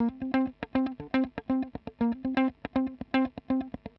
120_Guitar_funky_riff_C_4a.wav